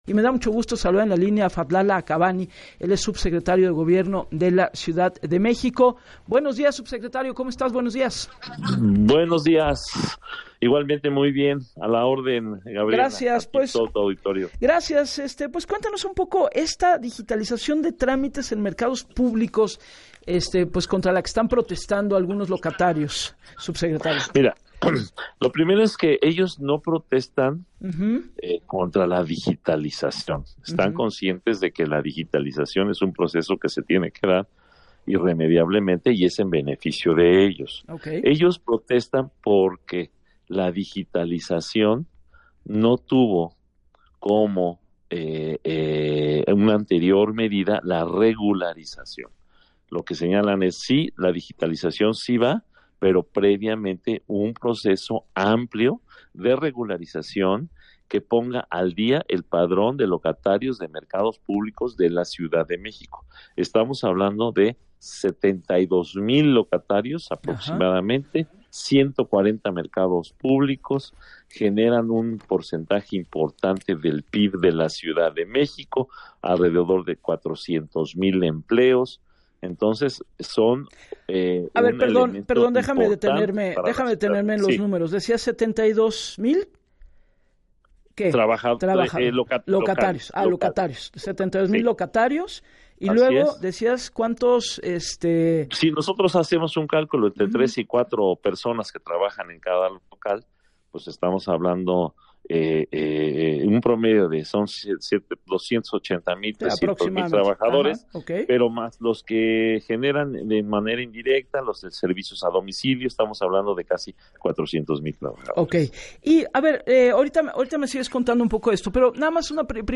En entrevista para “Así las Cosas” con Gabriela Warkentin, el funcionario reconoció que los 72 mil locatarios de 140 locales públicos generan un porcentaje importante el Producto Interno Bruto de la Ciudad de México, de la ciudad de México, con alrededor de 400 mil empleos y que no se niegan a la digitalización, “Ellos desean un proceso de regularización que les de certidumbre jurídica y les permita acceder a la digitalización para garantizarles el usufructo de cada local comercial”.